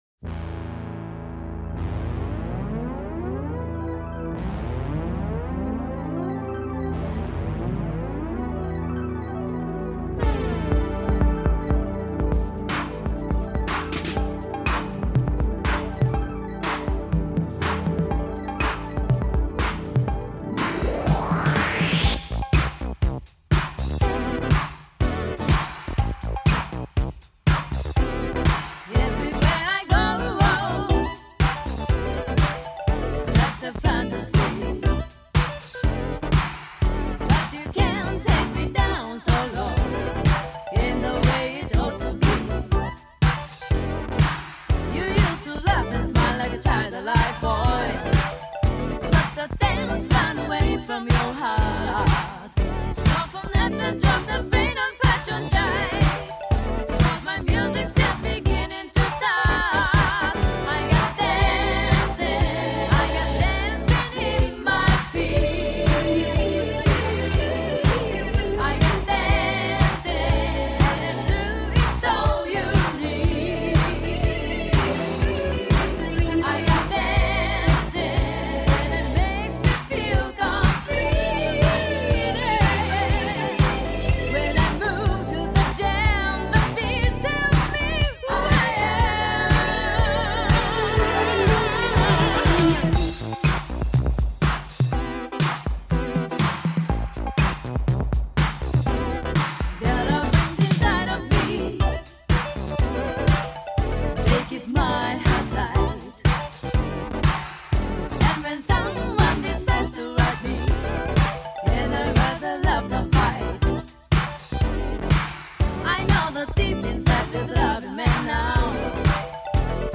ジャンル(スタイル) JAPANESE POP CLASSIC